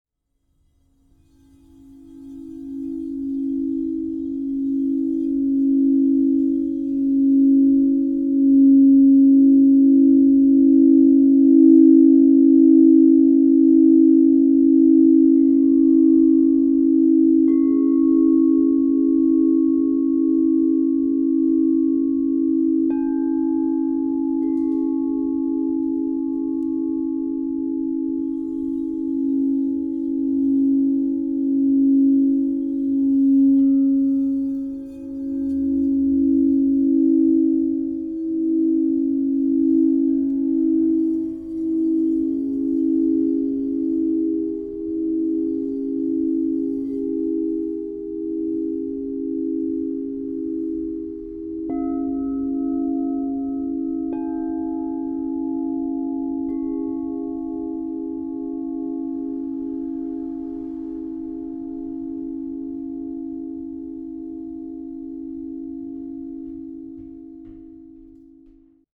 Prismatic Purification Crystal Tones® Harmonic Singing Bowl Set
This 3 Bowl Crystal Tones® alchemy singing bowl harmonic set includes: Tesseract Salt, Aqua Aura Gold 6″ G# -35 Crystal Tones Singing Bowl Egyptian Blue, Platinum, Ocean Gold (Inside) 8″ E -30 Crystal Tones Singing Bowl Platinum 9″ C# -20 Crystal Tones Singing Bowl Buy as a set and save!